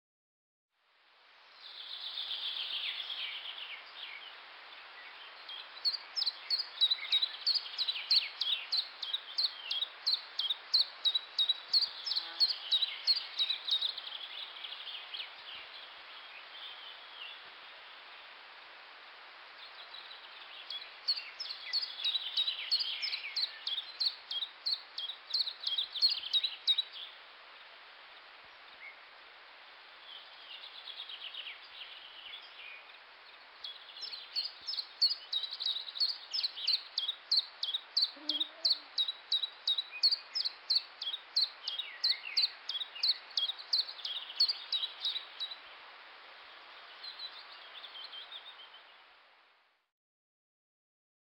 Kuuntele: Tiltaltin laulu etenee ryhdikkäästi